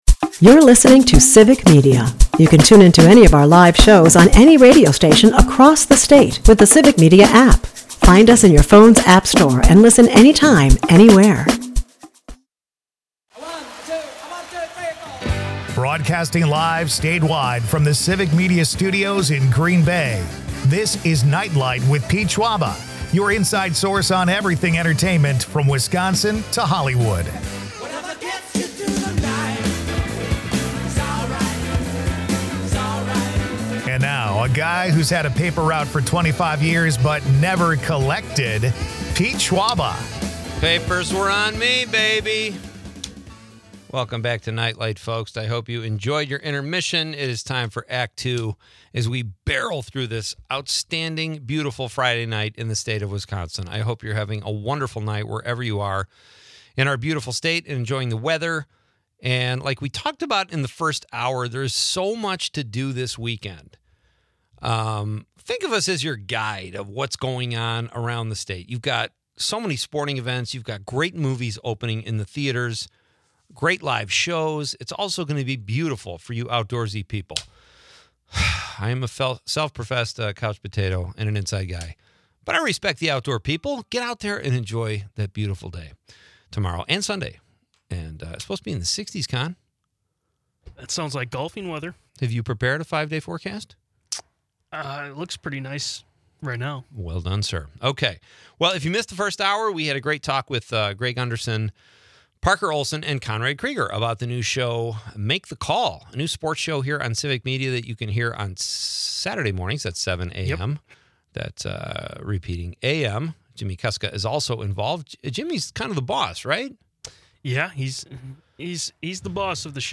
Plus, listeners weigh in on their favorite cakes, adding a sweet twist to the show. A blend of local charm and national flair, this episode captures the vibrant essence of Wisconsin's cultural offerings.
Leave the stress of the day behind with entertainment news, comedy and quirky Wisconsin.